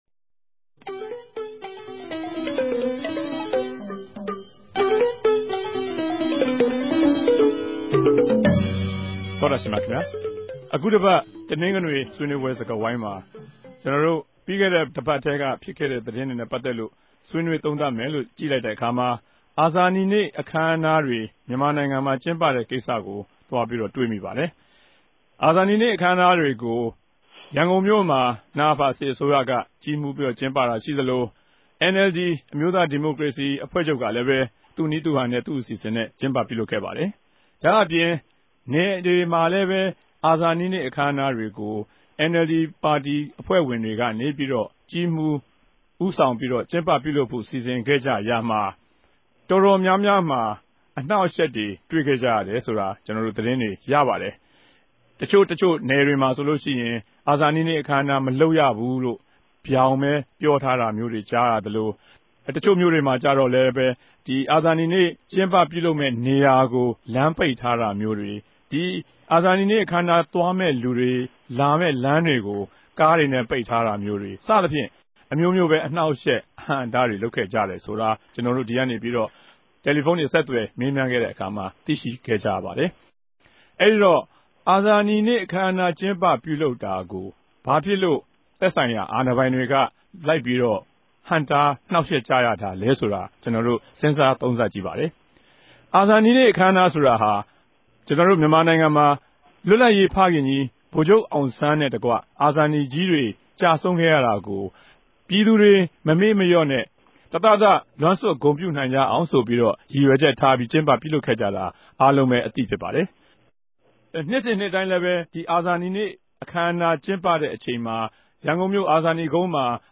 RFA ဝၝရြင်တန်႟ုံးခဵြပ် စတူဒီယိုထဲကနေ
ဆြေးေိံြးပြဲစကားဝိုင်း